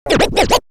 Scratch 24.wav